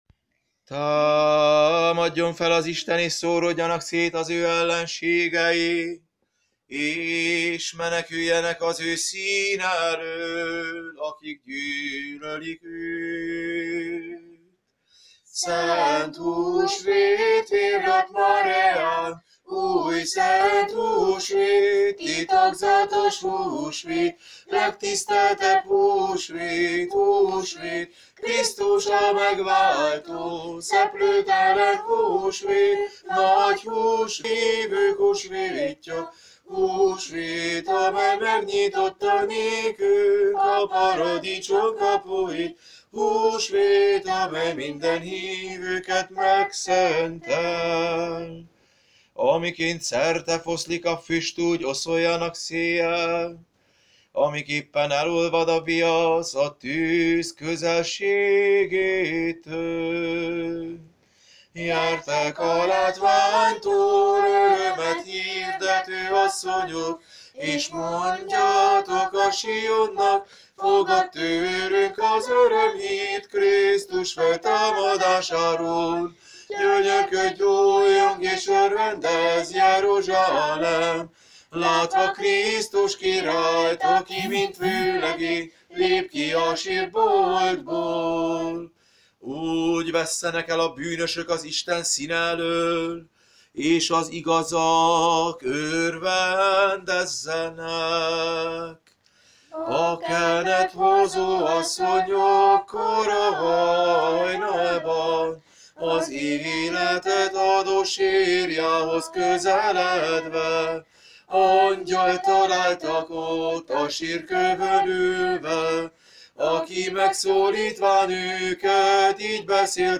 2026 Húsvétja Szegeden
Húsvéti sztihirák:
Húsvéti_sztichirák.mp3